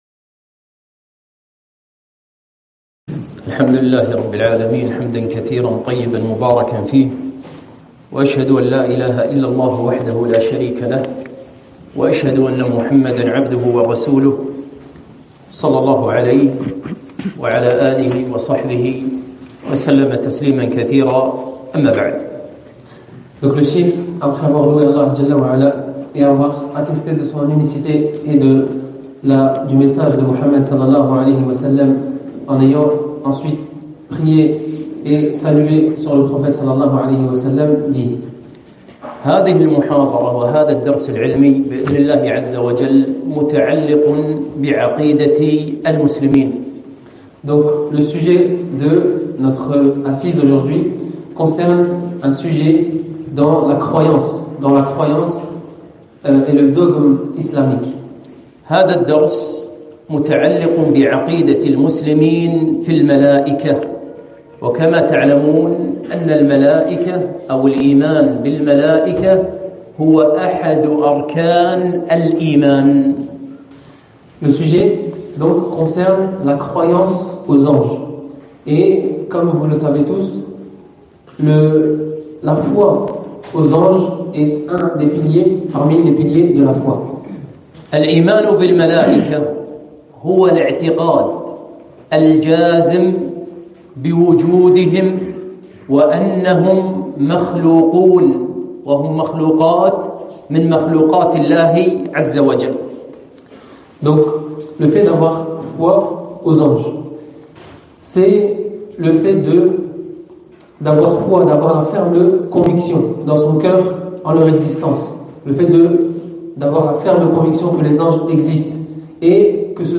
محاضرة - عقيدة المسلمين في الملائكة (مترجمة للفرنسية)